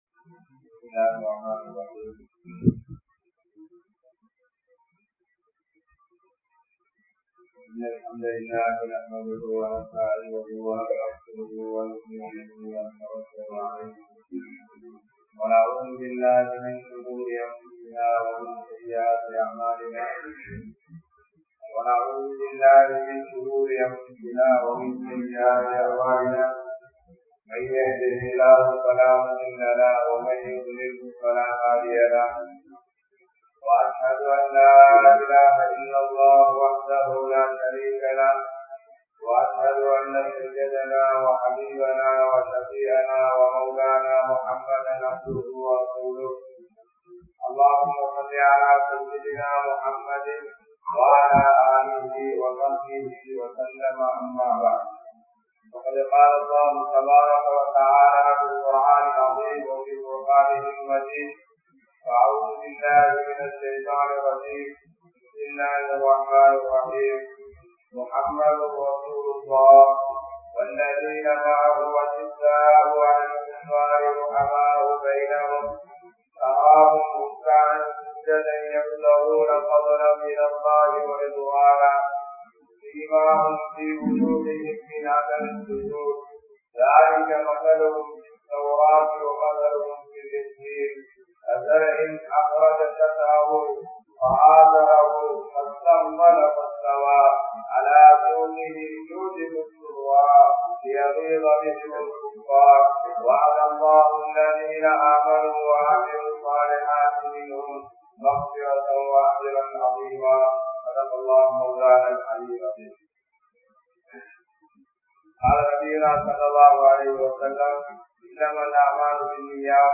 Dhauwaththin Avasiyam (தஃவத்தின் அவசியம்) | Audio Bayans | All Ceylon Muslim Youth Community | Addalaichenai
Wellawaya, Town Jumua Masjidh